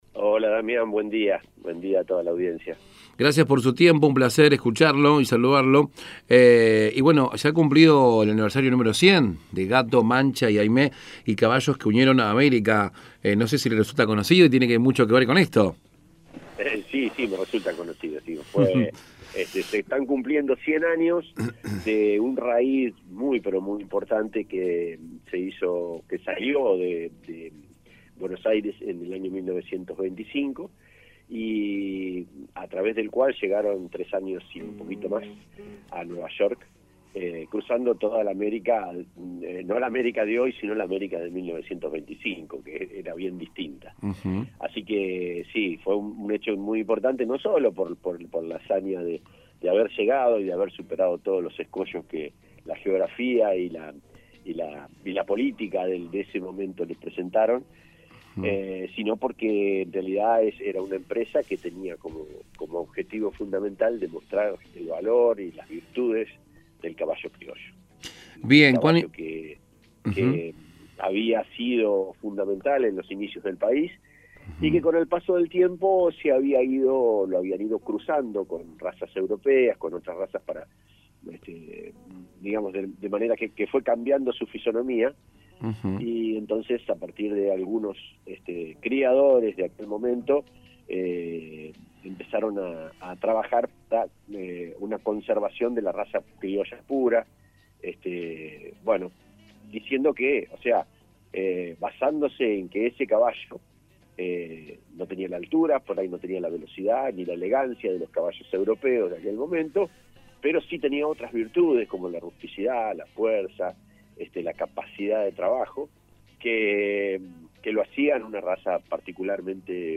Pablo Zubiaurre, ex intendente, profesor de historia y escritor. Hablo con en Radio Las Flores, donde nos conto sobre su libro “Gato, Mancha y Aimé” Nota completa más abajo.